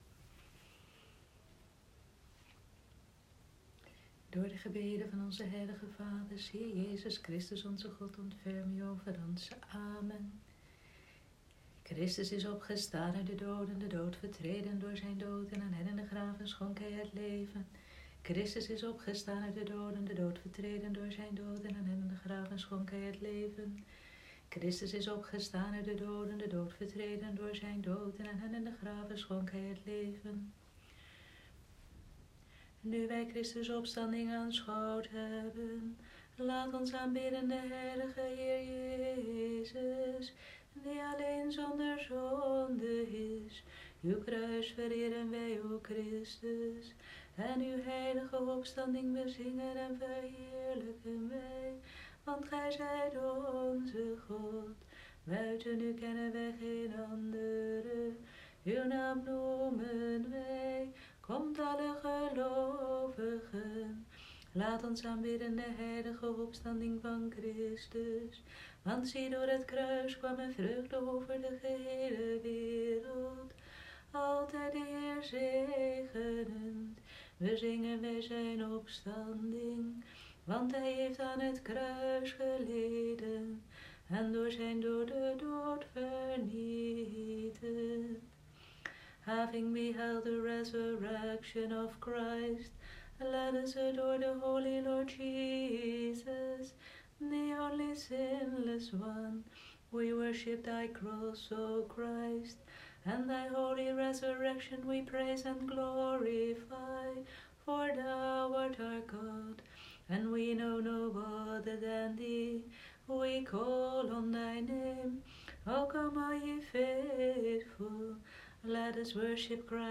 Paasuur en Vespers van Stralende Donderdagavond, 23 april 2020 - Orthodox Aartsbisdom van België
Paasuur en Vespers van Stralende Donderdagavond, 23 april 2020